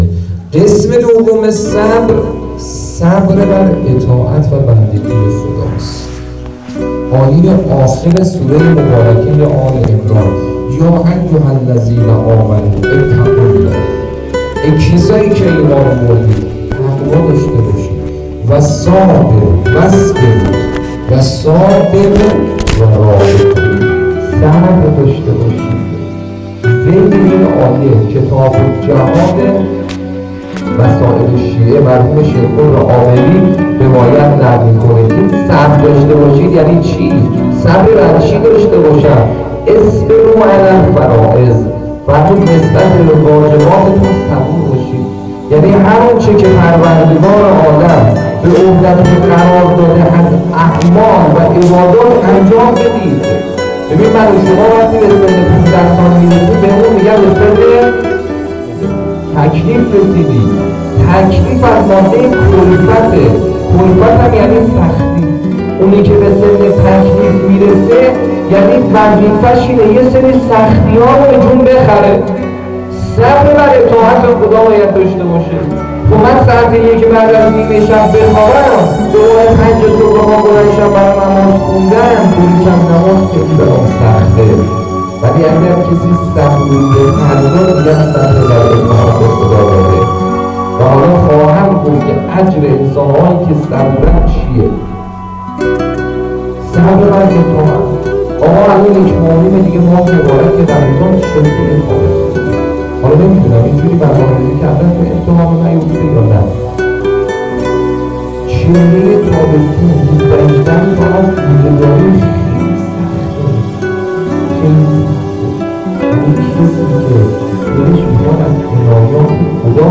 سخنرانی(صبر)2.wav
سخنرانی-صبر-2.wav